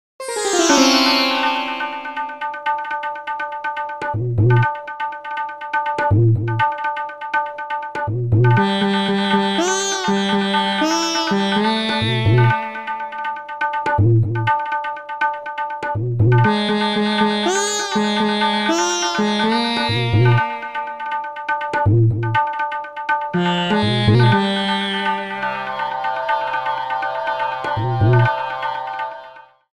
Trimmed, added fadeout
Fair use music sample